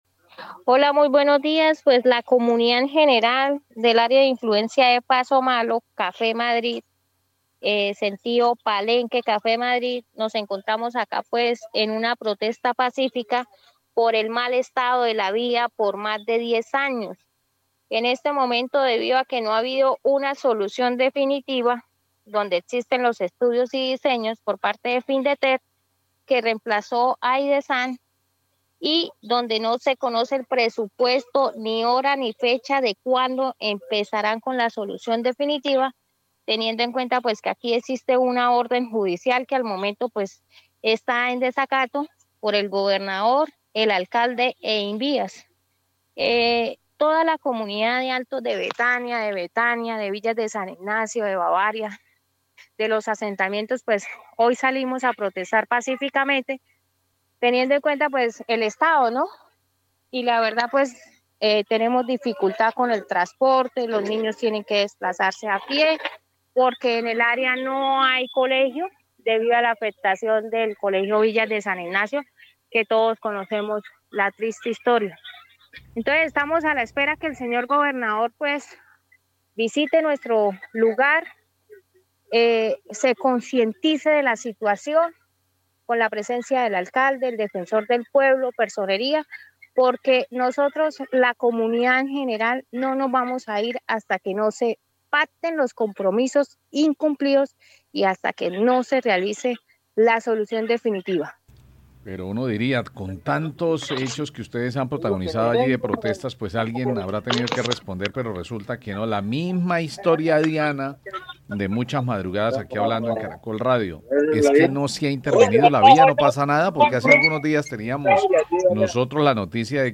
residente que está en la protesta